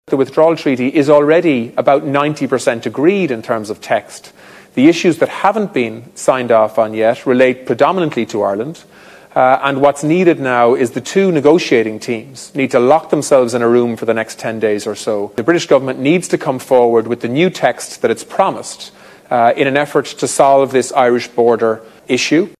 Simon Coveney says both negotiating teams need to lock themselves in a room and come up with a deal: